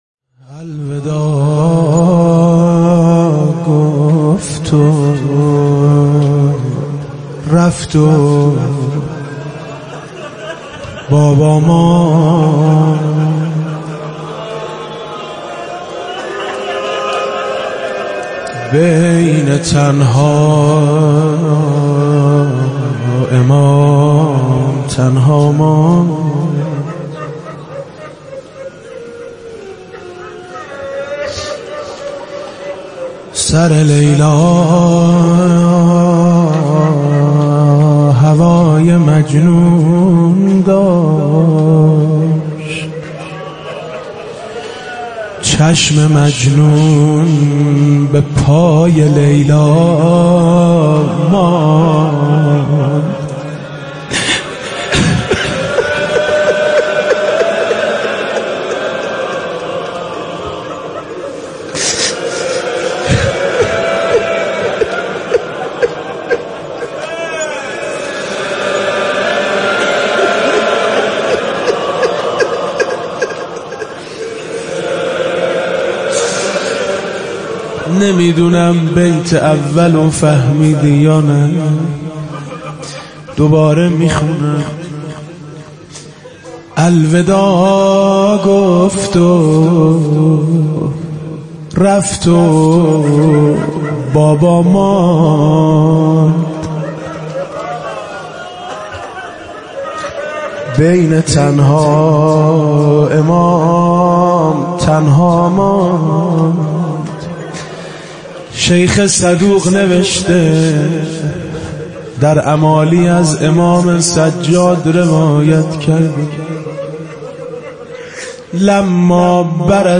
روضه حضرت علی اکبر علیه السلام با صدای میثم مطیعی -( الوداع گفت و رفت و بابا ماند )